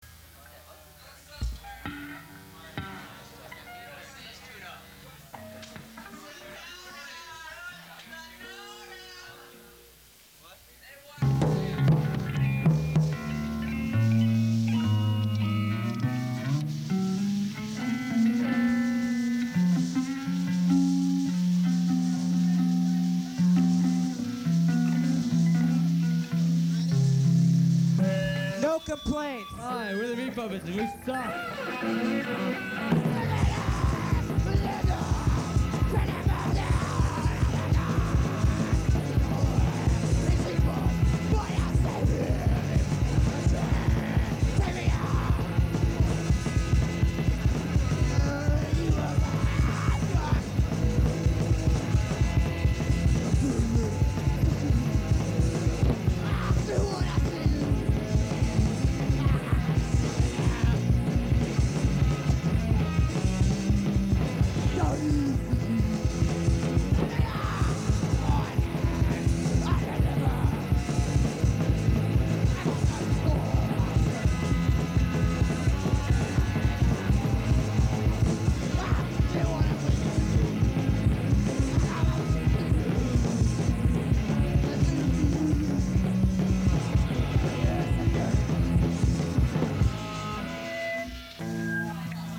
Quality is pretty damn good, considering it's from 1982.